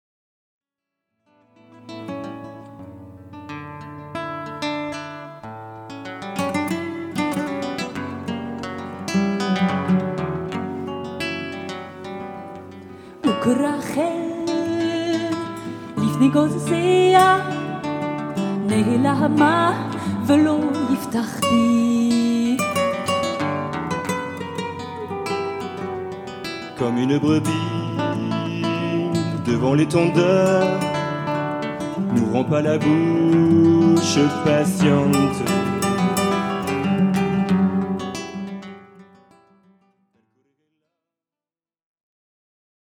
en live à la cathédrale de Chartres